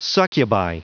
Prononciation du mot succubi en anglais (fichier audio)
Prononciation du mot : succubi